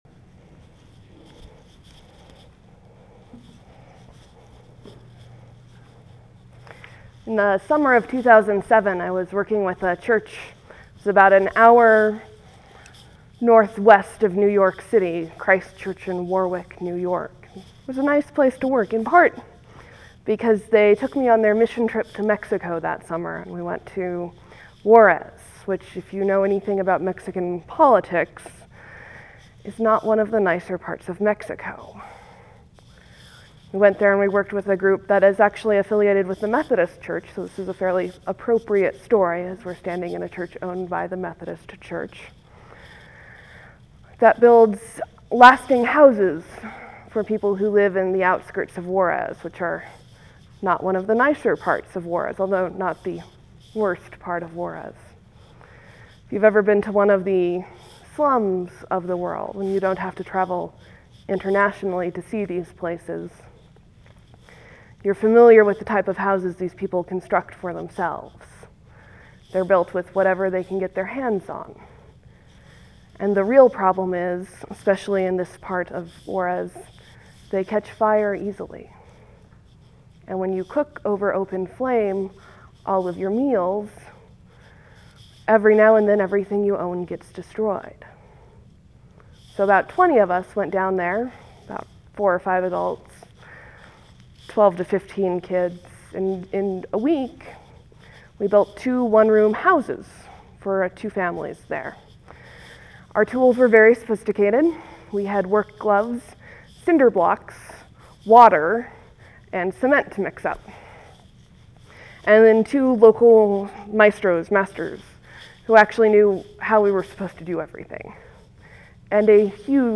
Lent, Sermon, , , , Leave a comment
(There will be a few seconds of silence before the sermon starts. Thank you for your patience.)